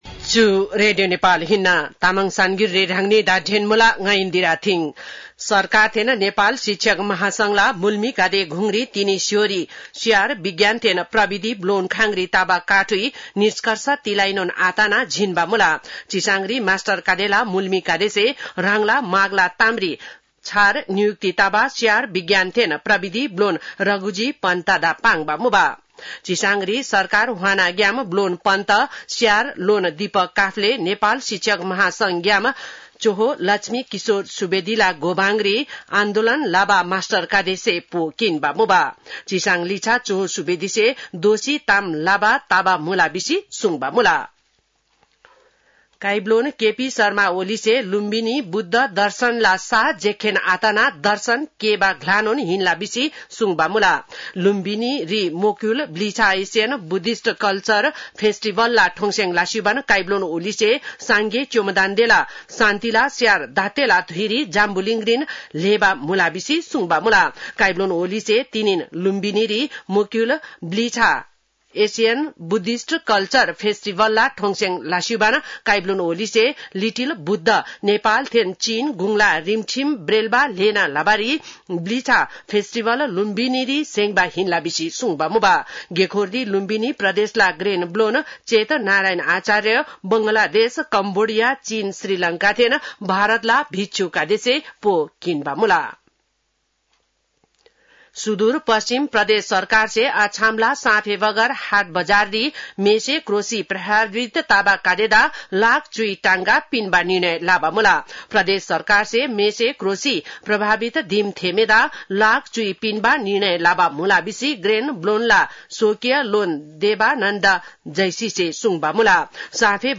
तामाङ भाषाको समाचार : १३ वैशाख , २०८२